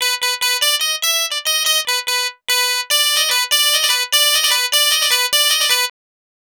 Twisting 2Nite 2 Clav-B.wav